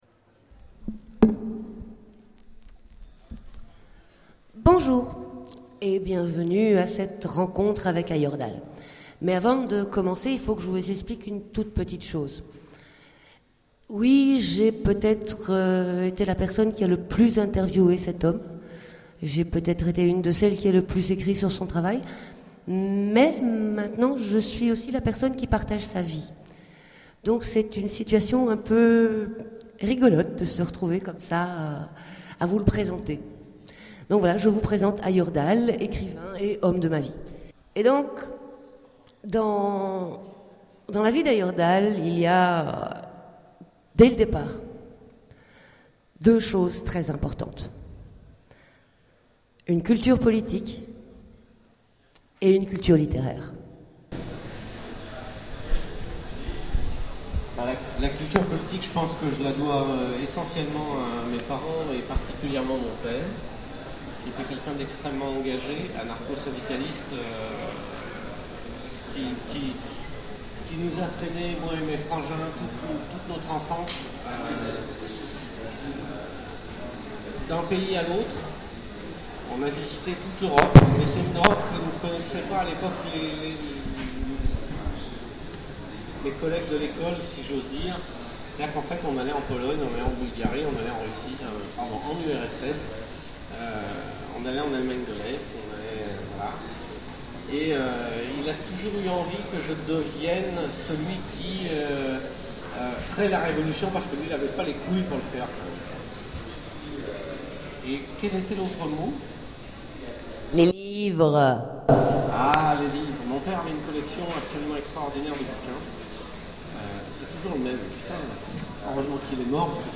Utopiales 13 : Conférence Rencontre avec Ayerdhal
- le 31/10/2017 Partager Commenter Utopiales 13 : Conférence Rencontre avec Ayerdhal Télécharger le MP3 à lire aussi Yal Ayerdhal Genres / Mots-clés Rencontre avec un auteur Conférence Partager cet article